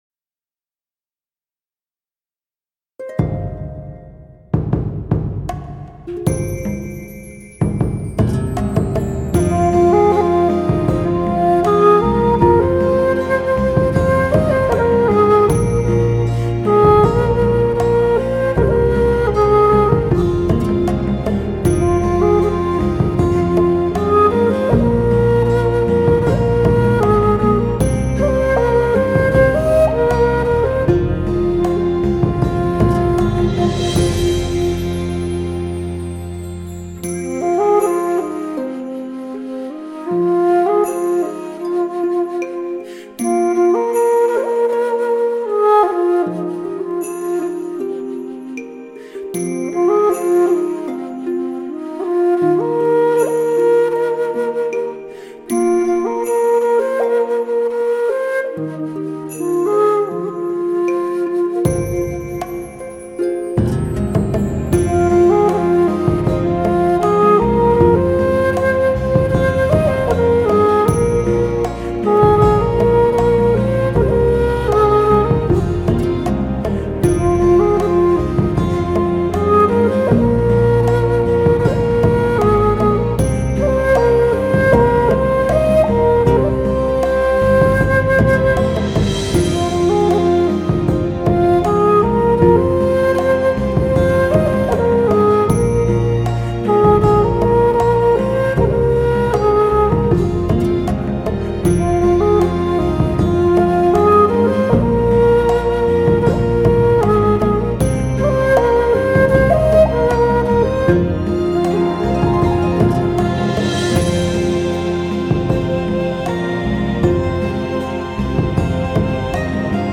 乐器：箫